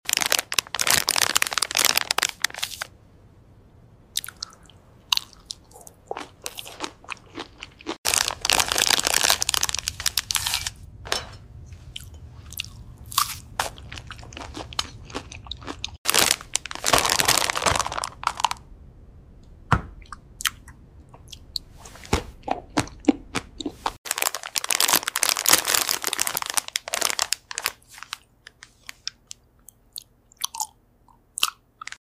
Batman Eats Joker Fruit Snacks ASMR